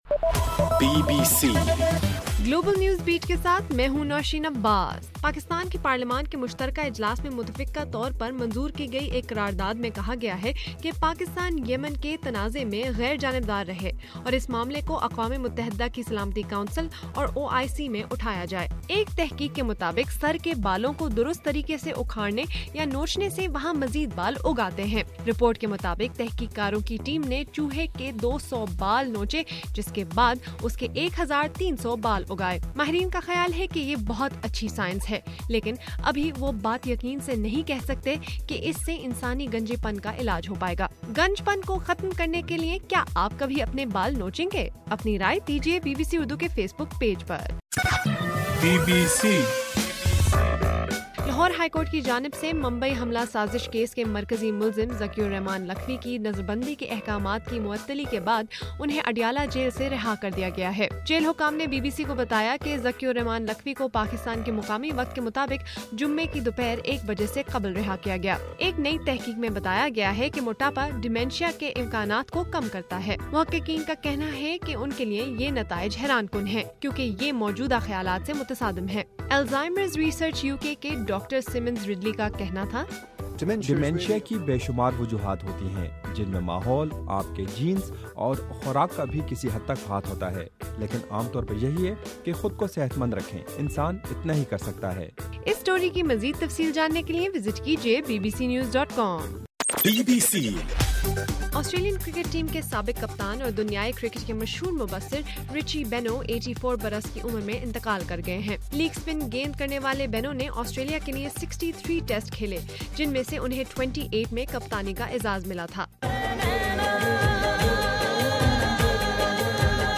اپریل 10: رات 10 بجے کا گلوبل نیوز بیٹ بُلیٹن